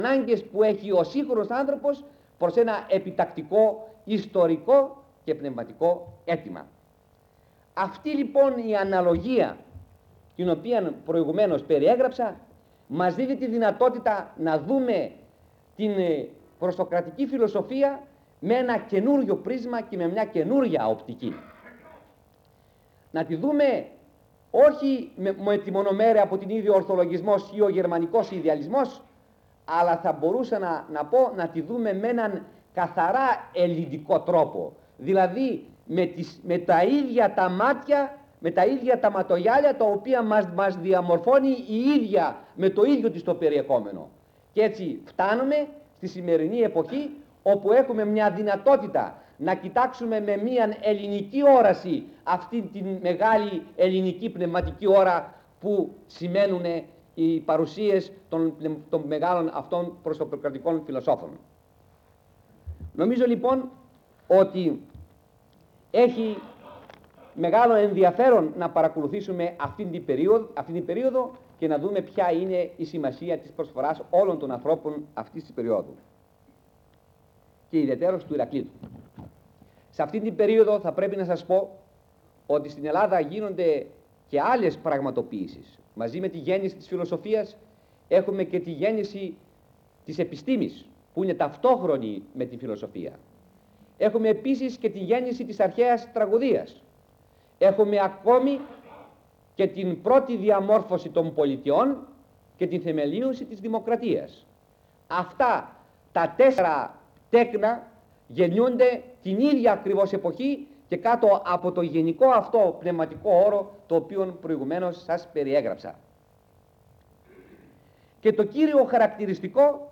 Περιγραφή: Ηχογράφηση του πρώτου από τα τέσσερα μαθήματα